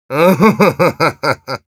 Spy_laughshort02_ru.wav